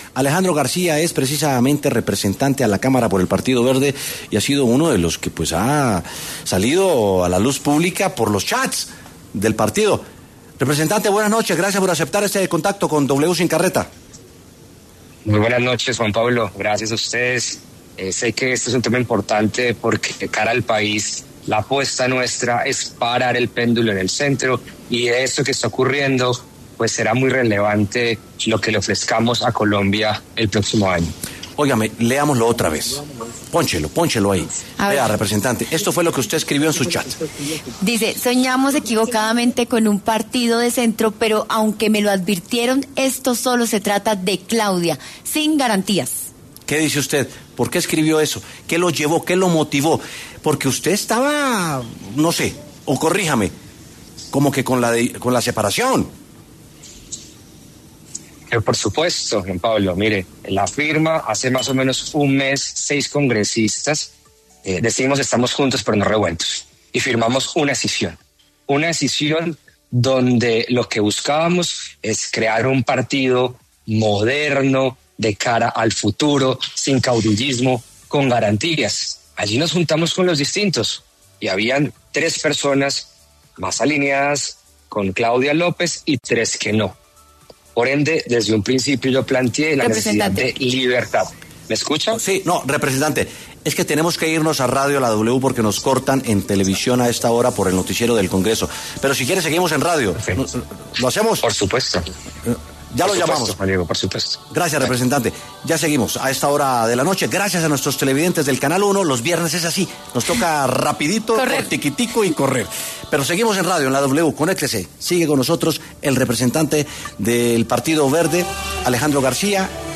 Alejandro García, representante a la Cámara de la Alianza Verde, habló en los micrófonos de W Sin Carreta sobre la ruptura que hay dentro del partido y lo que viene para las elecciones presidenciales de 2026.